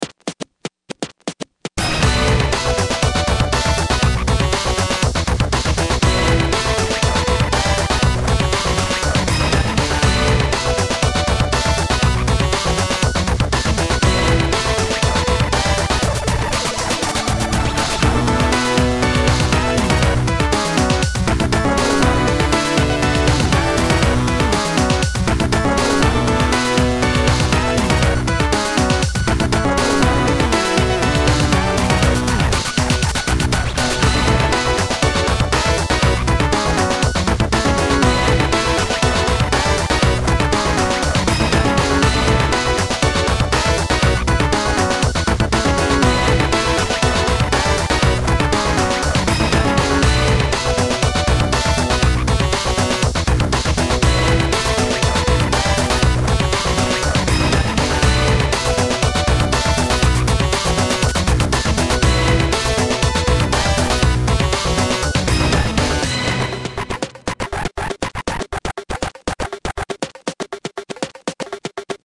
battle theme